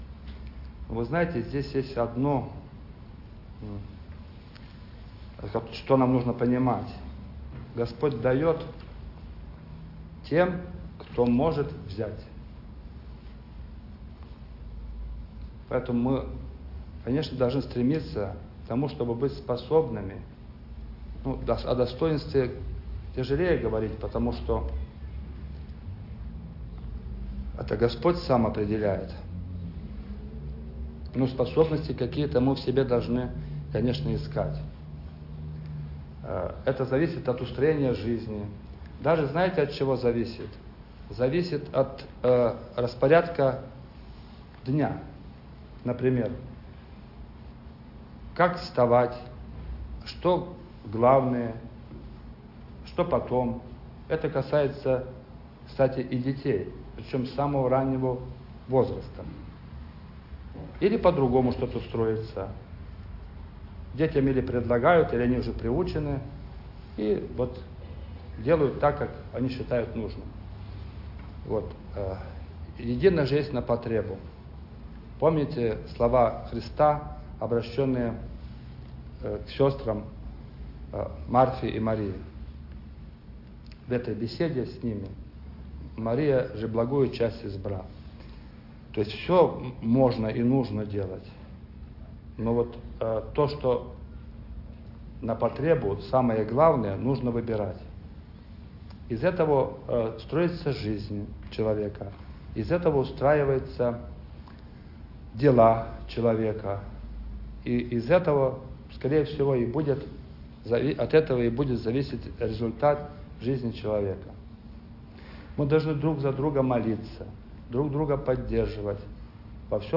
Напутственное слово